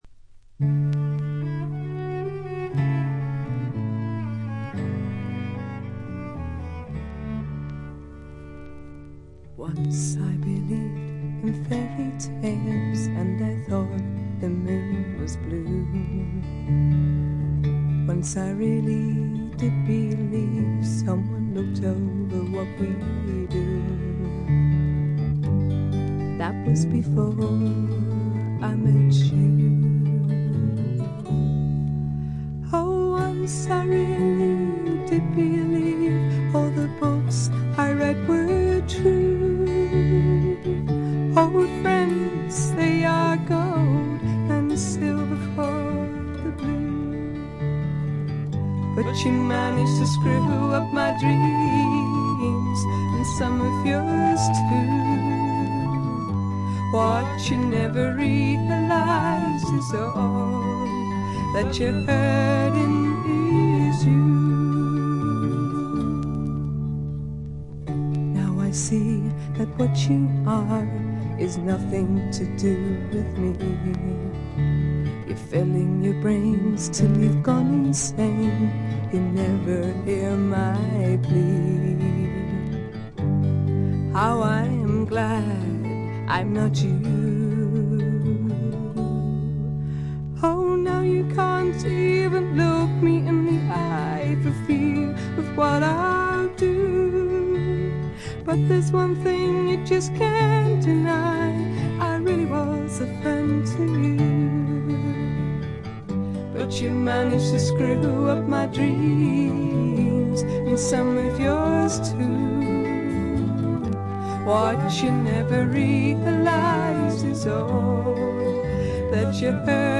試聴曲は現品からの取り込み音源です。
Cello